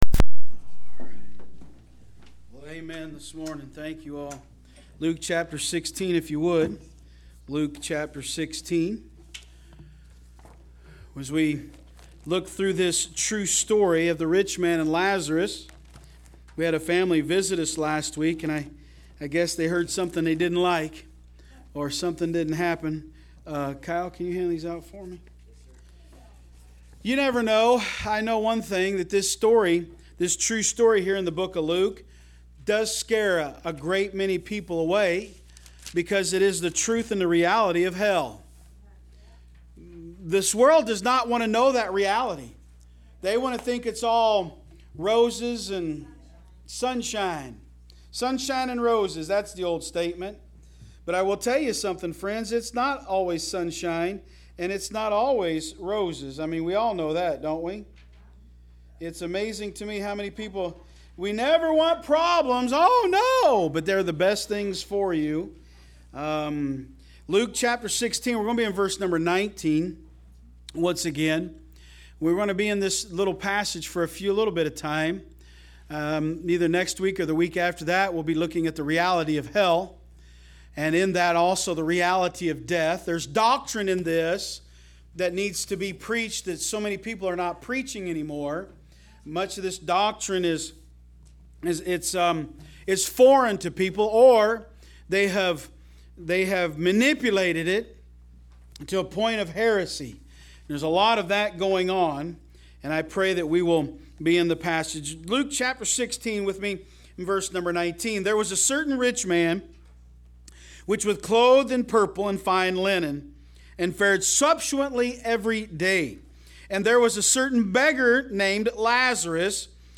Online Sermons – Walker Baptist Church
From Series: "AM Service"